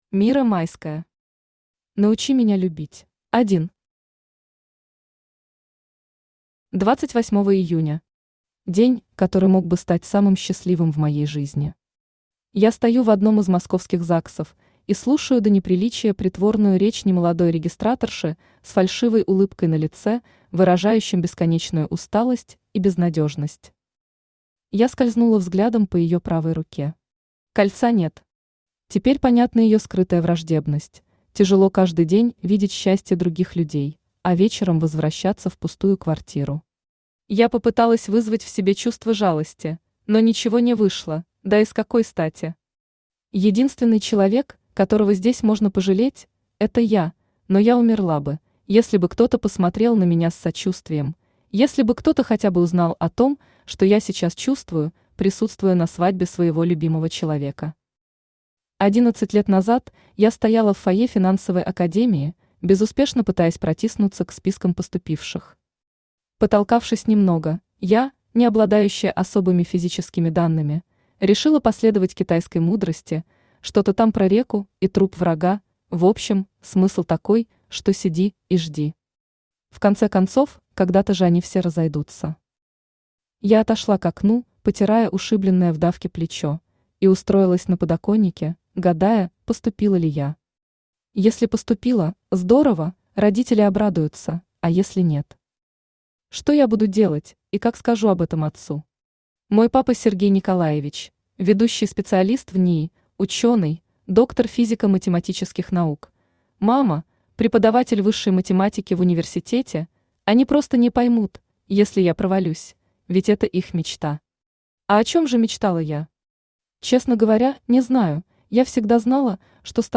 Aудиокнига Научи меня любить Автор Мира Майская Читает аудиокнигу Авточтец ЛитРес.